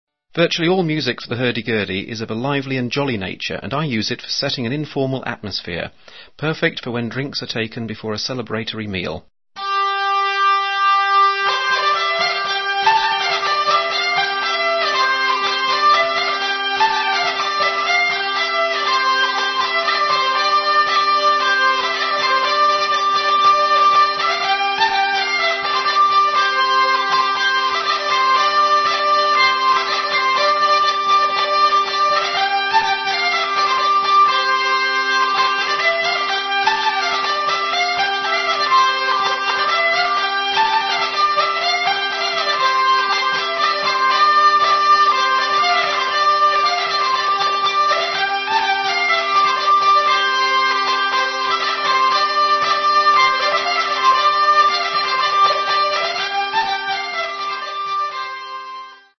An Elizabethan gentleman specialising in the stringed instruments of the renaissance and early baroque periods.